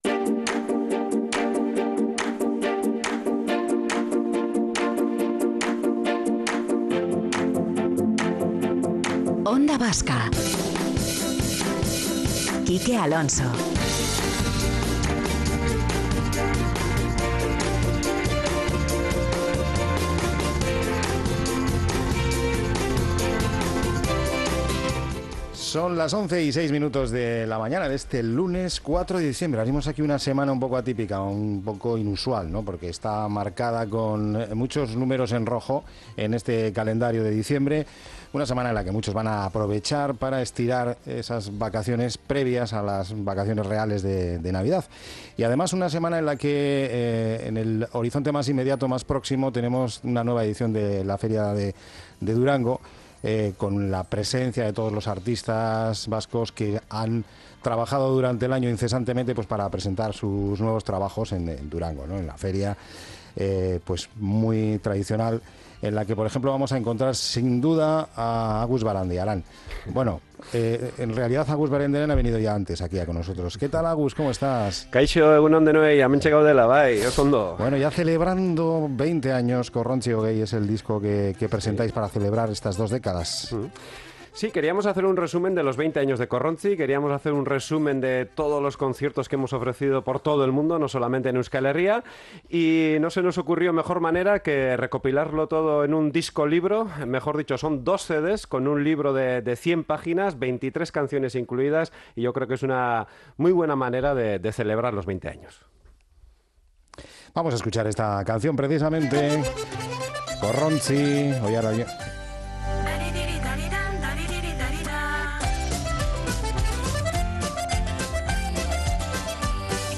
en los estudios de Onda Vasca